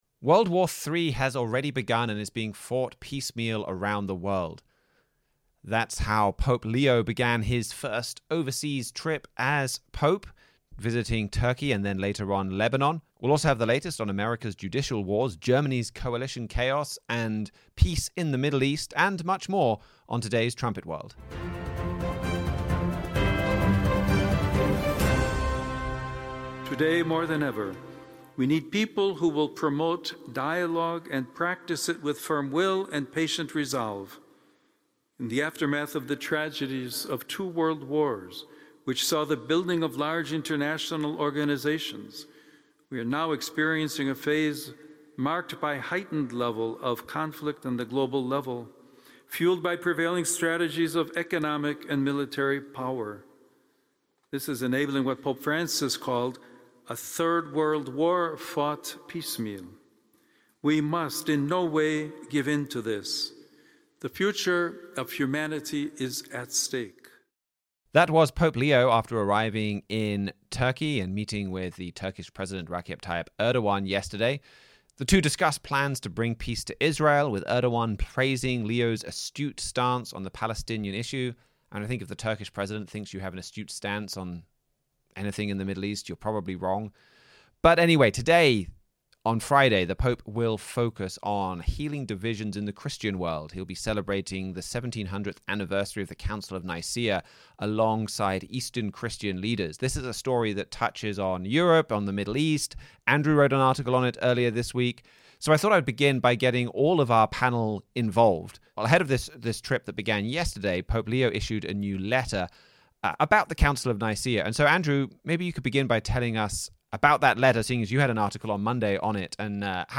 World War III has already begun and is being fought piecemeal around the world, Pope Leo said during his first overseas trip as pope. The panelists on Trumpet World dissect his visit to Turkey.